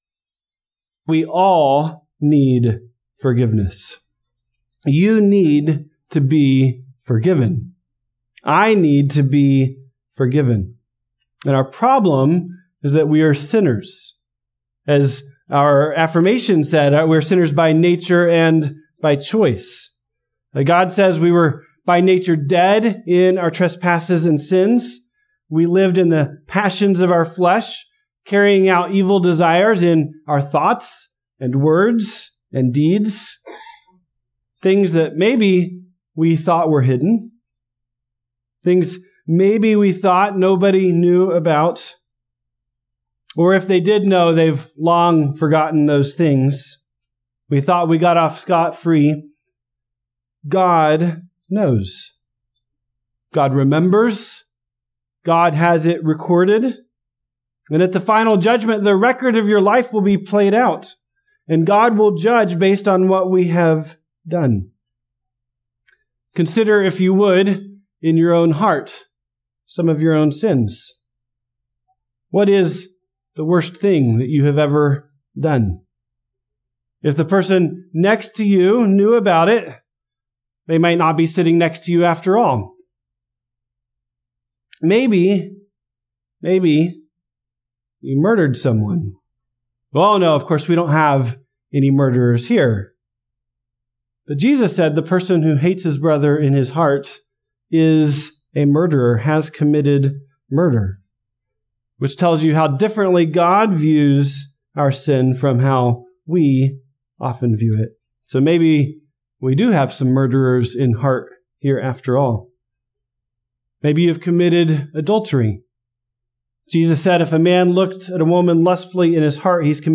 Bible Text: Mark 2:1-12 | Preacher